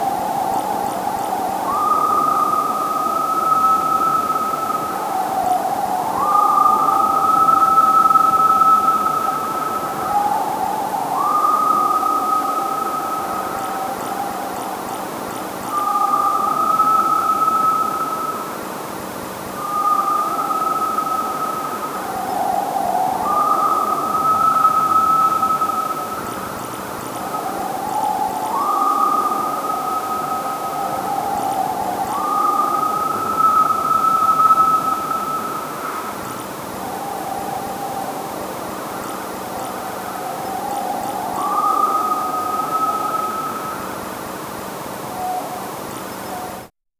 distant owl night
distant-owl-night-54g5prhi.wav